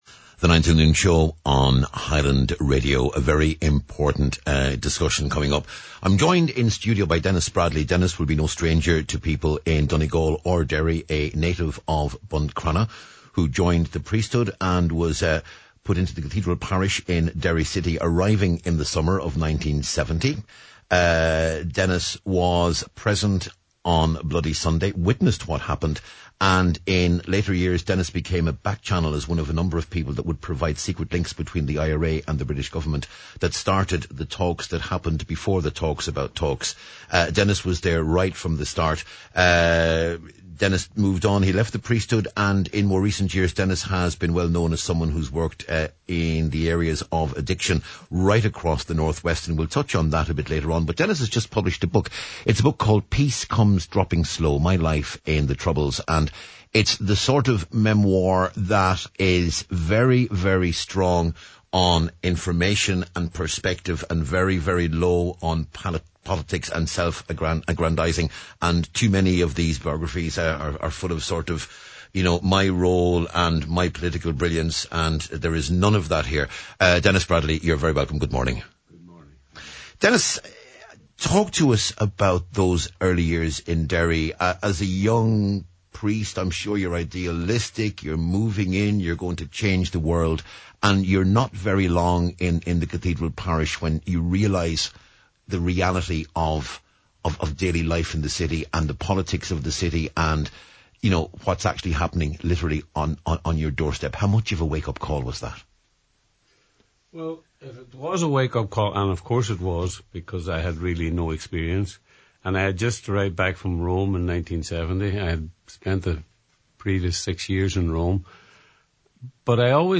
The Nine ’til Noon Show – Weekday’s 9am to 12noon Magazine type mid morning chat show which aims to inform and entertain listeners and to platform their views and issues: